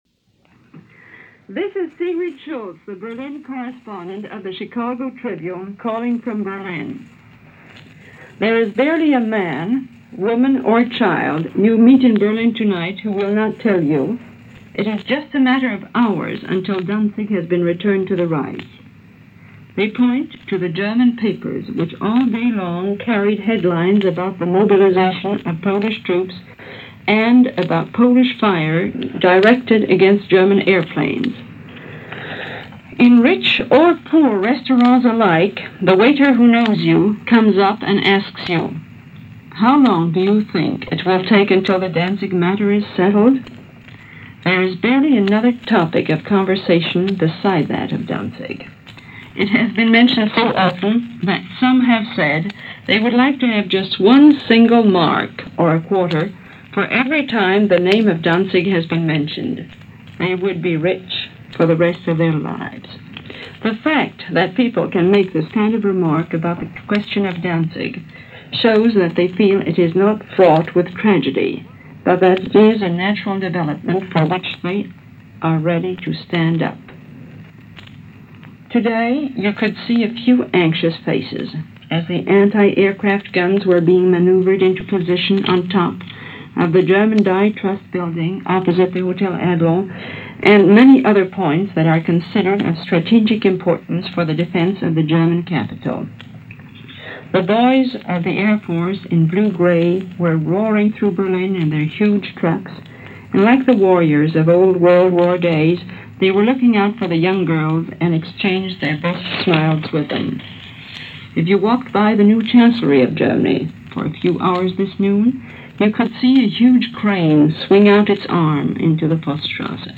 As reported by Berlin Correspondent Sigrid Schultz for the Chicago Tribune.
And that’s a sample of what was observed in Berlin, for this August 24, 1939 as reported by Sigrid Schultz for the Mutual Broadcasting company direct from Germany.